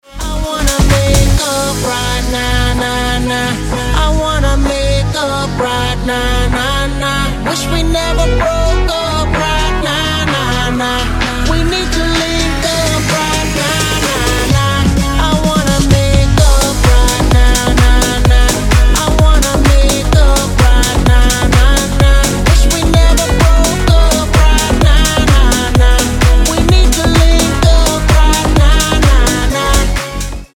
Яркий танцевальный ремикс